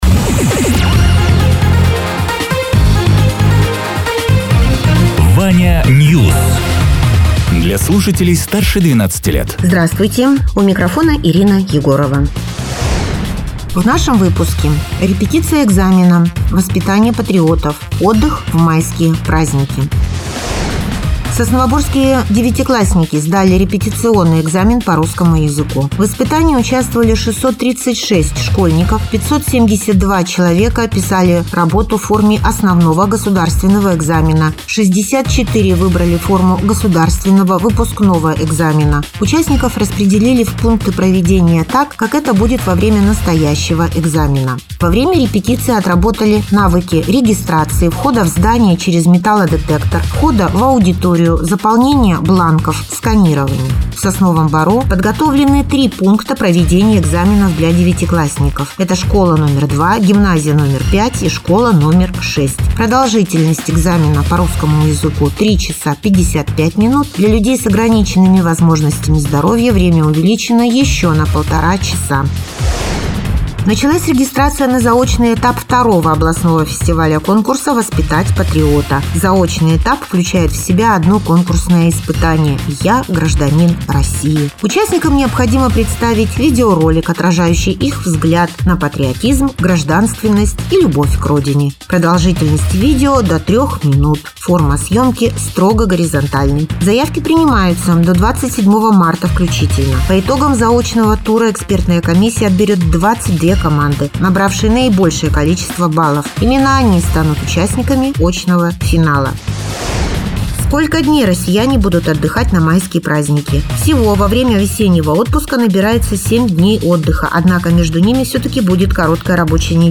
Радио ТЕРА 14.03.2026_10.00_Новости_Соснового_Бора